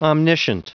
Prononciation du mot omniscient en anglais (fichier audio)
Prononciation du mot : omniscient